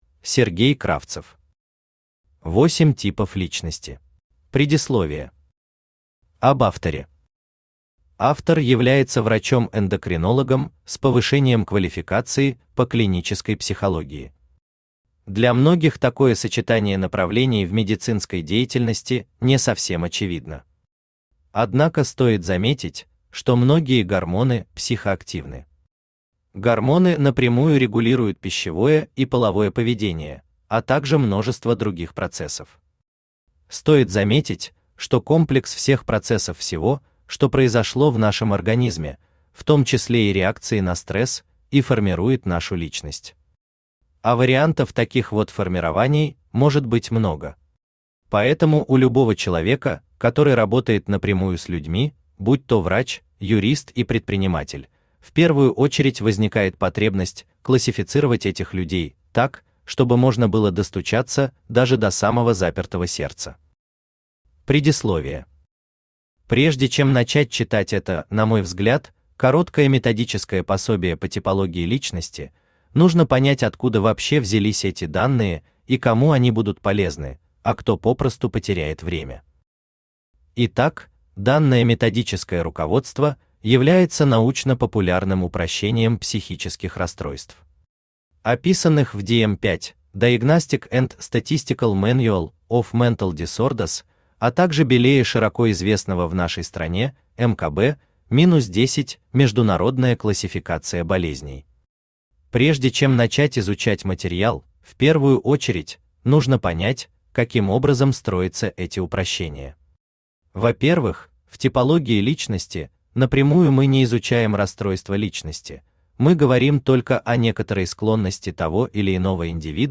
Аудиокнига Восемь типов личности | Библиотека аудиокниг
Aудиокнига Восемь типов личности Автор Сергей Олегович Кравцев Читает аудиокнигу Авточтец ЛитРес.